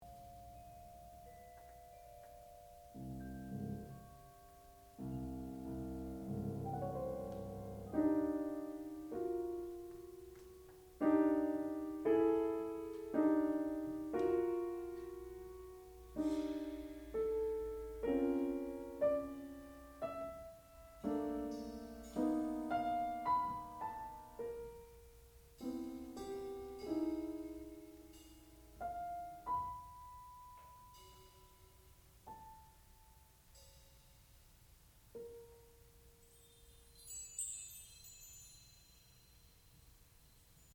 Seven Scenes for Piano and Percussion
sound recording-musical
classical music